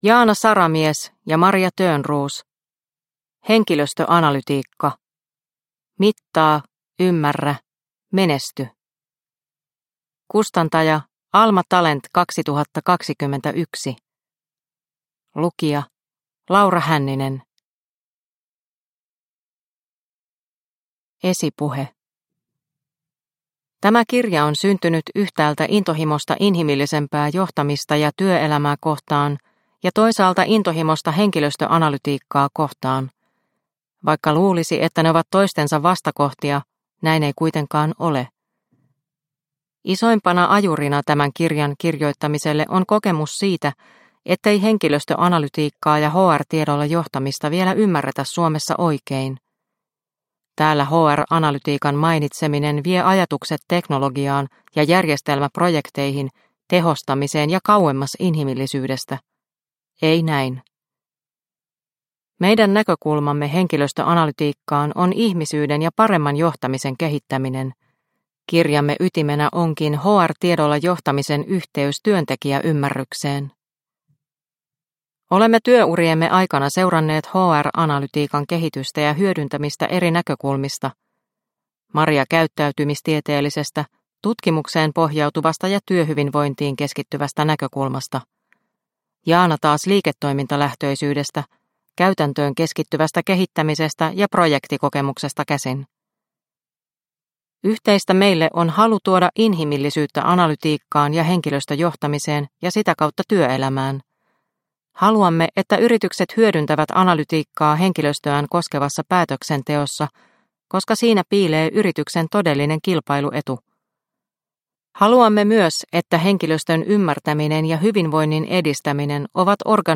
Henkilöstöanalytiikka – Ljudbok – Laddas ner